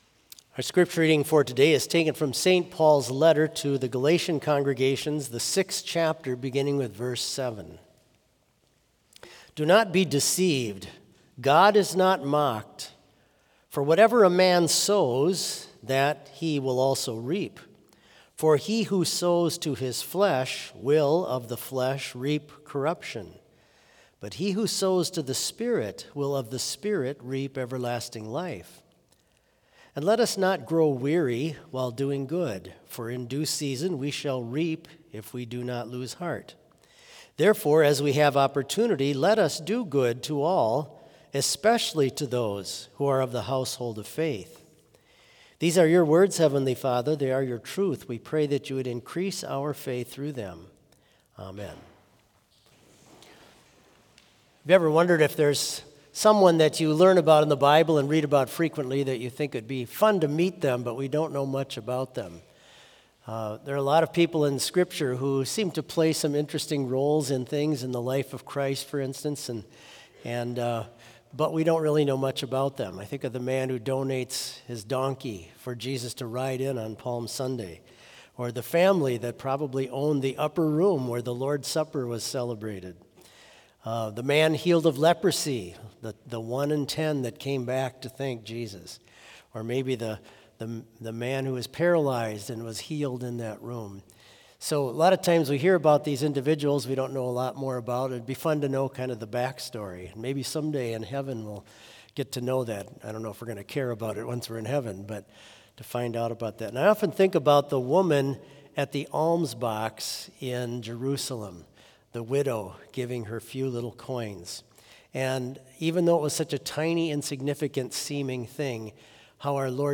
Complete service audio for Chapel - Monday, August 25, 2025